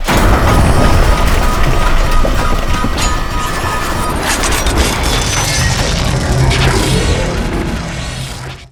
fall.wav